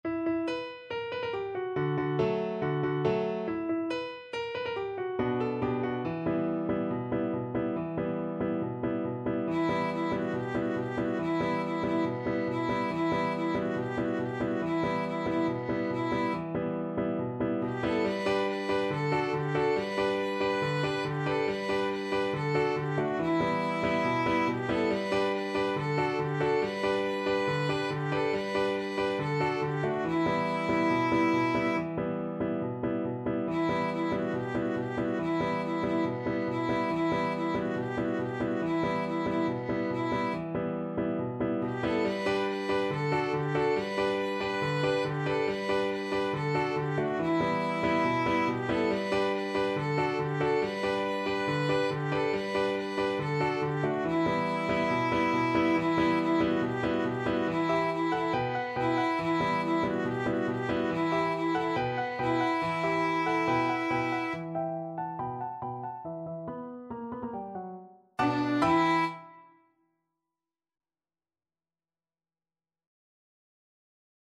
4/4 (View more 4/4 Music)
Very Fast =c.140
Classical (View more Classical Violin Music)
Israeli